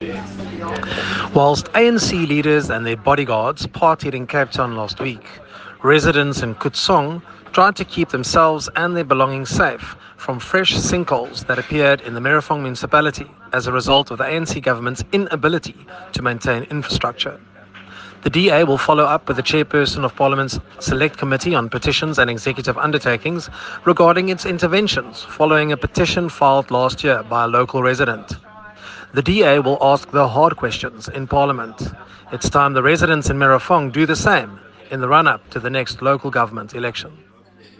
Note to Editors: Please find English and Afrikaans soundbites by Nicholas Gotsell MP
Nicholas-Gotsell-MP_English_Sinkholes-in-Merafong.mp3